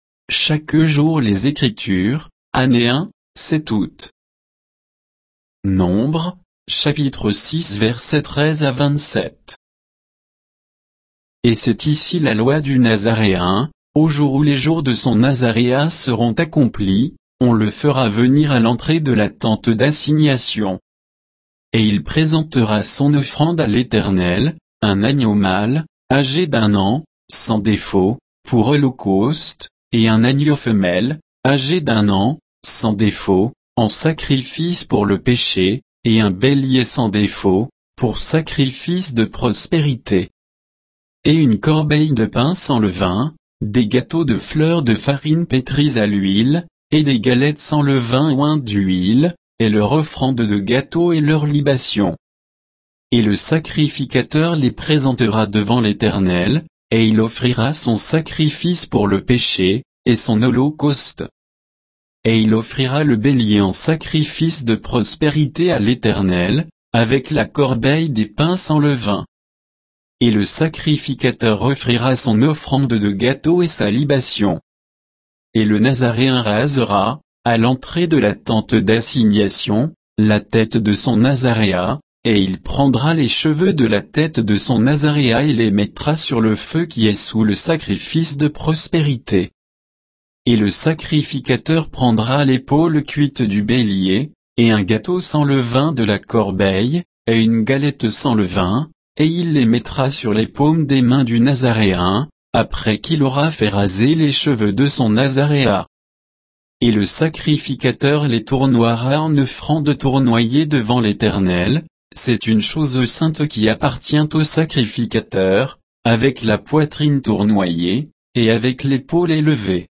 Méditation quoditienne de Chaque jour les Écritures sur Nombres 6